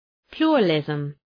Προφορά
{‘plʋrə,lızm}